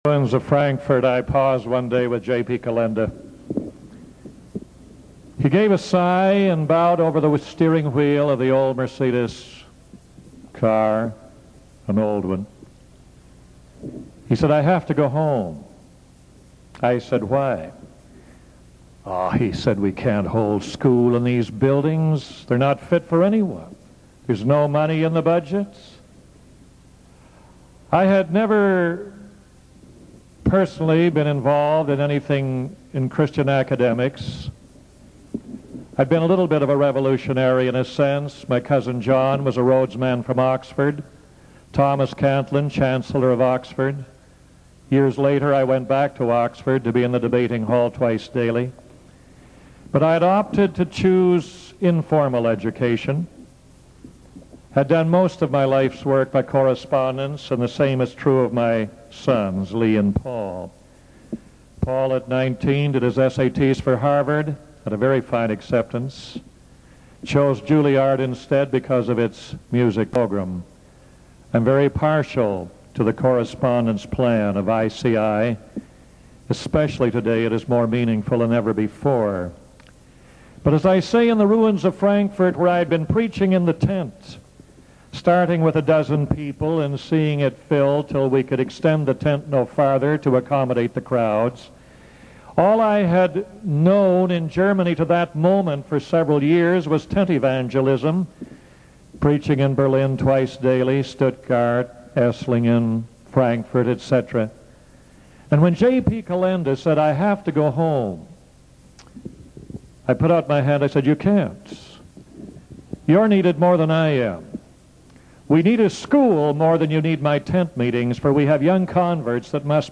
Featured Sermons